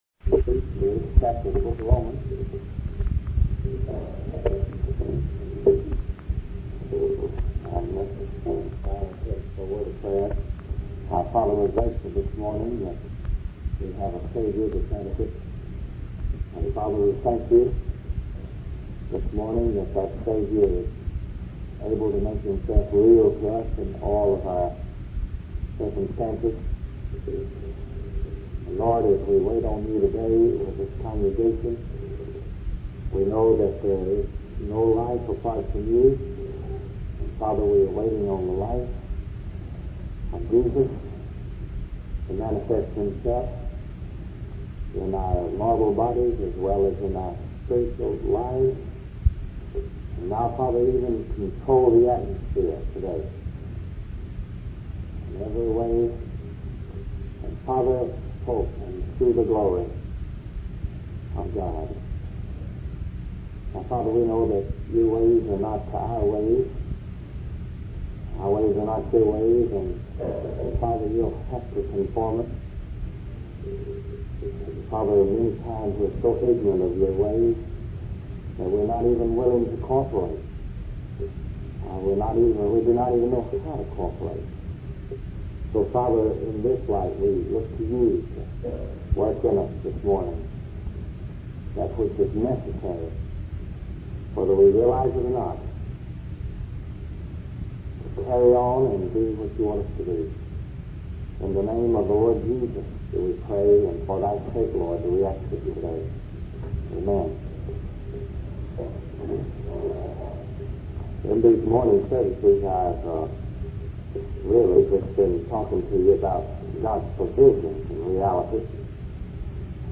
In this sermon, the preacher discusses the laws that operate in the universe and how they affect our behavior. He emphasizes that we don't have to do anything to lose our temper or be wrong because it is a natural law at work within us.